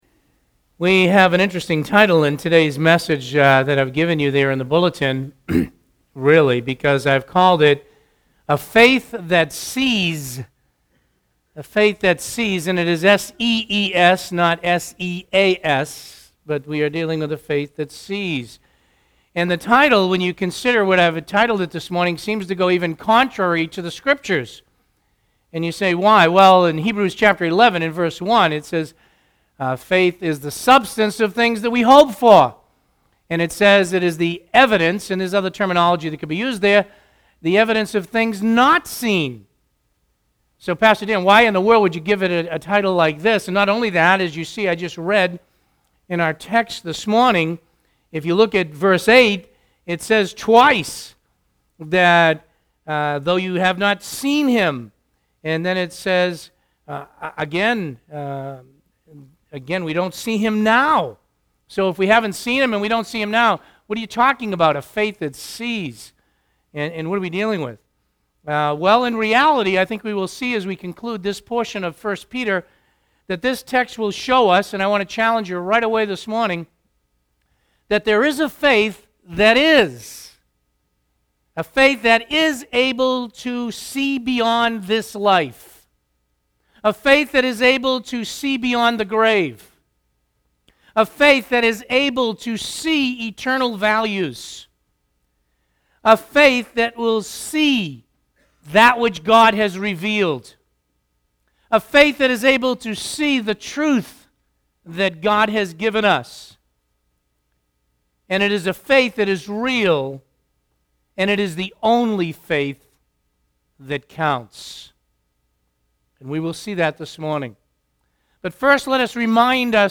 Listen to the sermon “A Faith That Sees.”